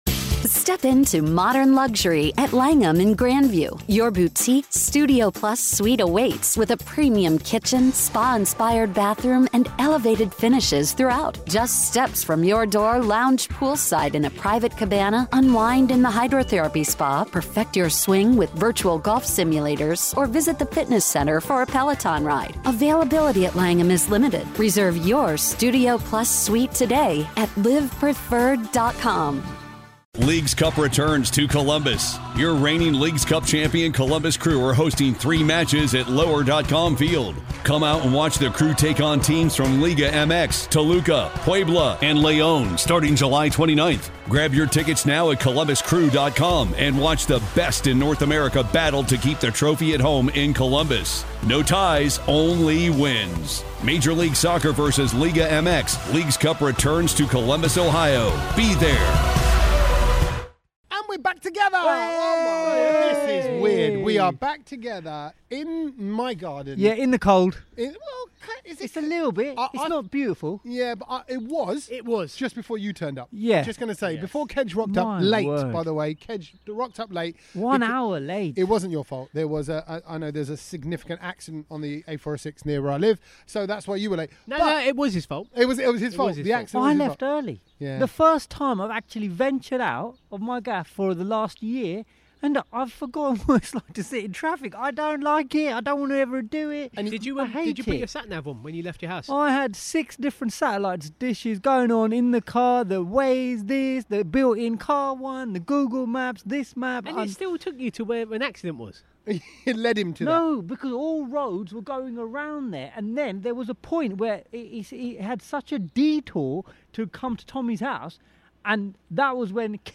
After what feels like ages, this is our first podcast back at my house (in the garden!) altogether!
We’re not playing any music anymore in the podcasts and wanted to give you our catch up chat uninterrupted!...